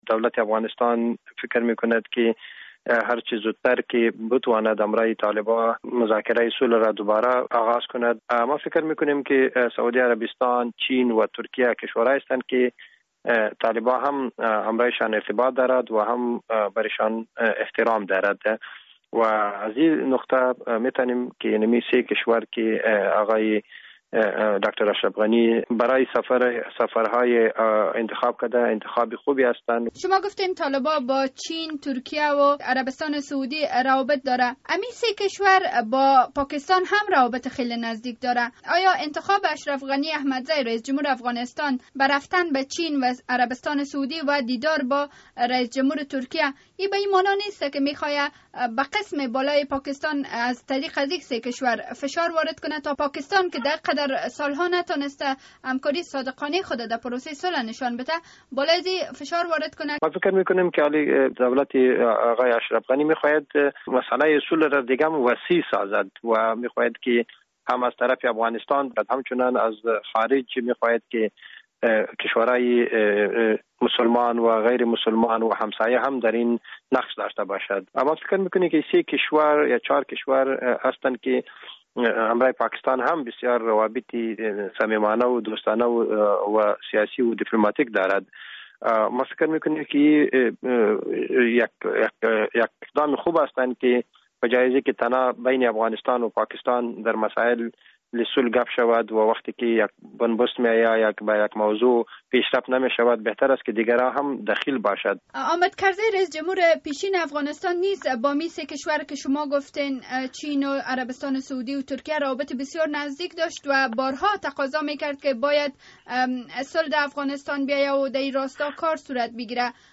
مصاحبه در مورد نقش برخی از کشورهای منطقه در تامین صلح در افغانستان